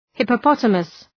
Προφορά
{,hıpə’pɒtəməs}